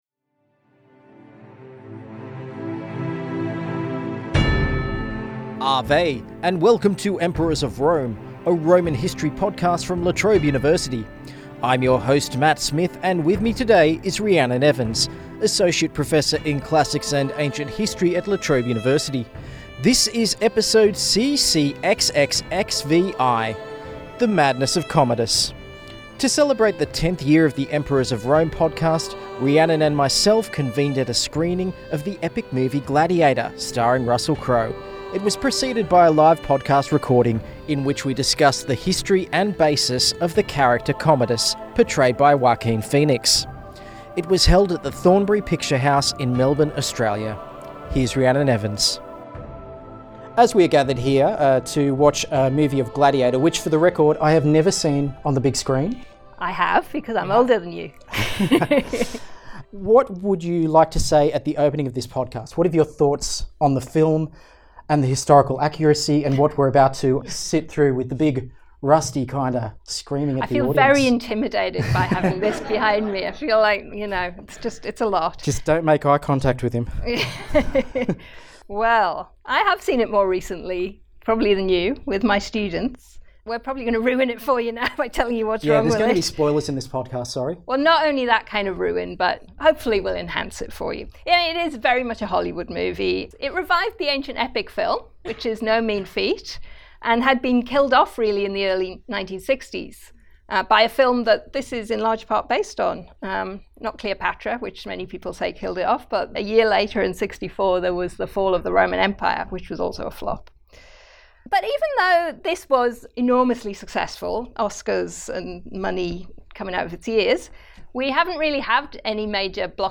Episode CCXXXVI - The Madness of Commodus (Live in Melbourne)
Recorded on the 19th October 2024 at the Thornbury Picture House, Melbourne.